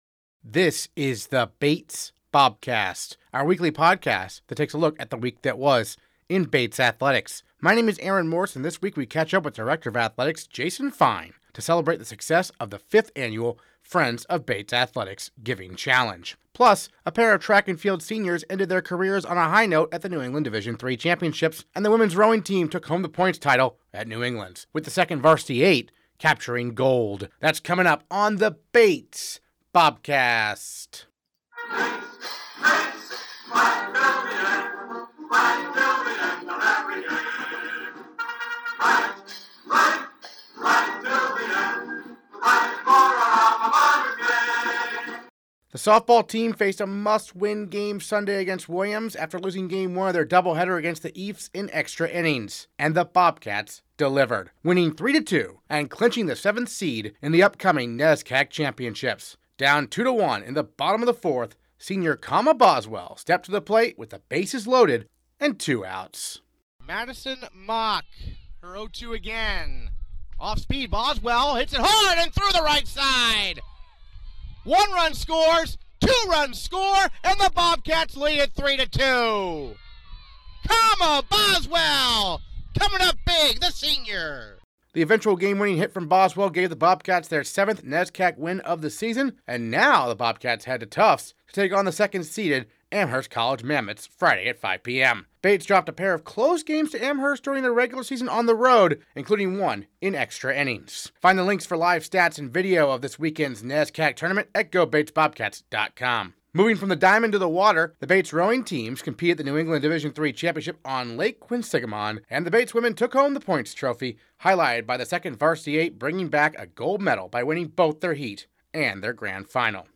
Plus, a pair of track and field seniors ended their careers on a high note at the New England Division III Championships, and the women's rowing team took home the points title at New Englands, with the second varsity eight capturing gold. Interviews and highlights this episode: